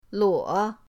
luo3.mp3